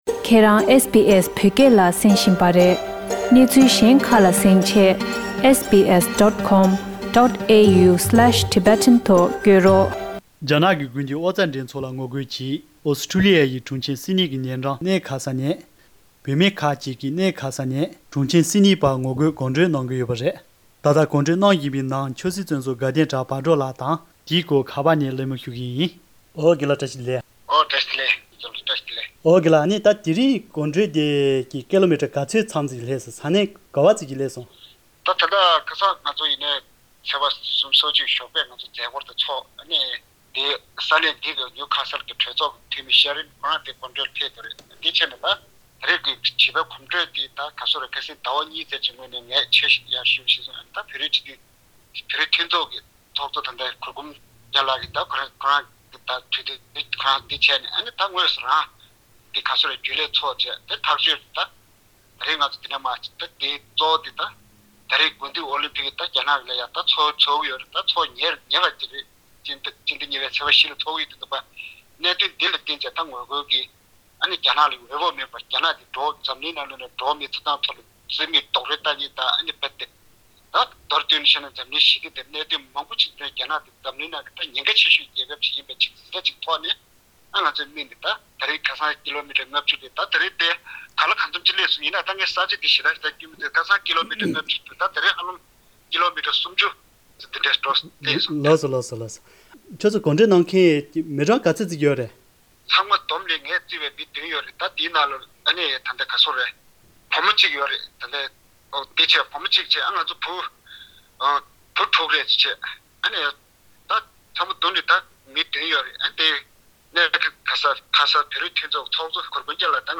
ཁ་པར་ཐོག་ནས་གླེང་མོལ་ཞུས་པ།